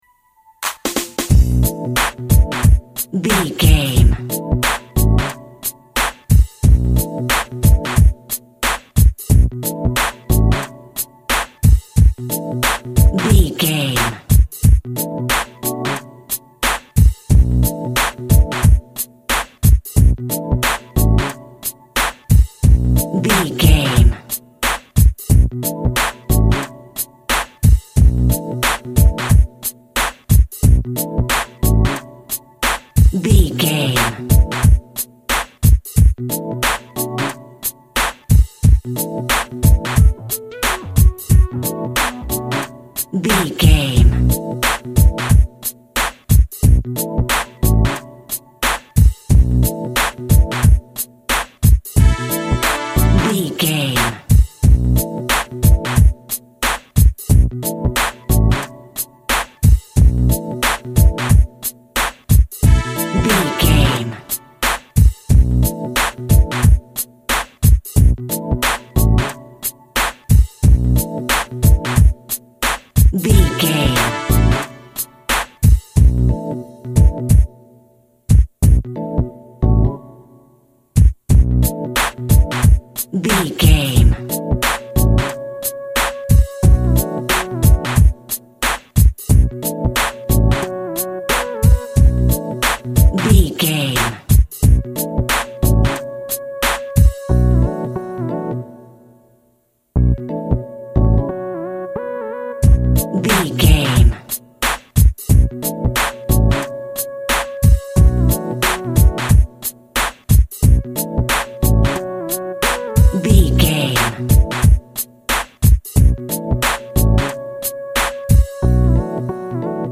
Rap Funk.
Aeolian/Minor
hip hop
synth lead
synth bass
hip hop synths